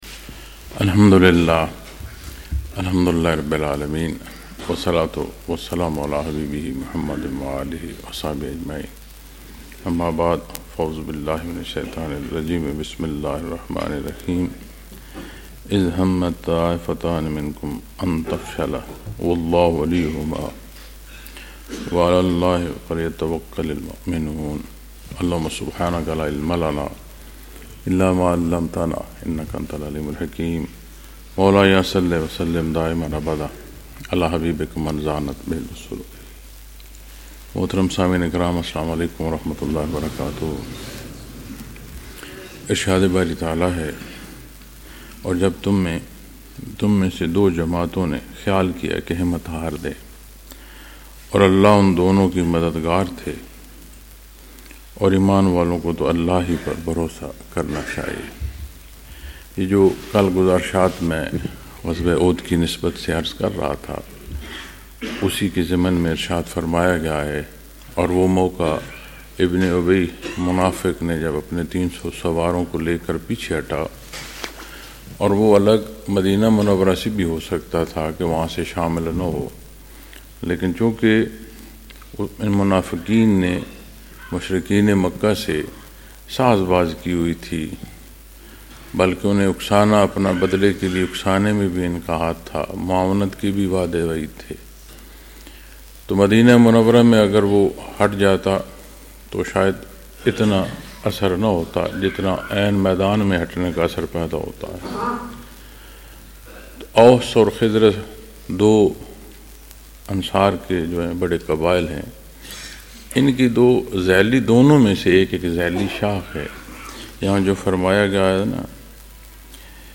Munara, Chakwal, Pakistan